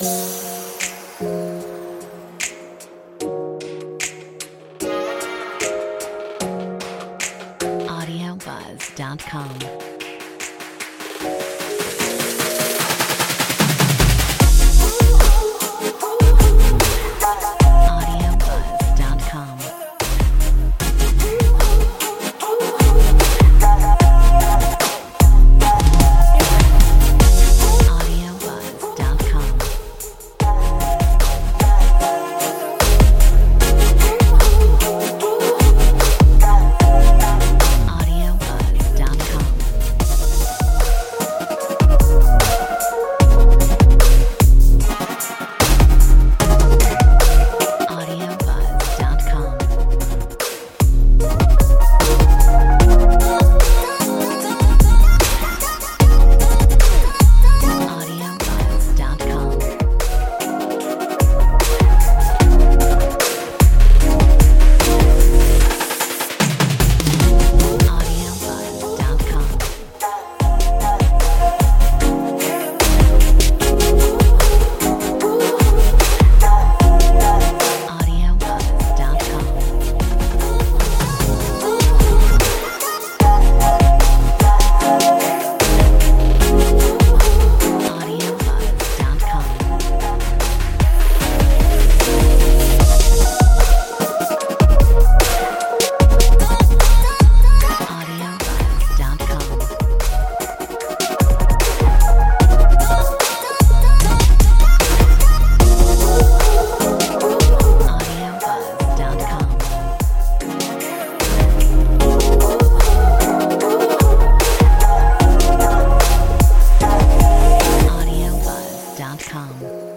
Metronome 75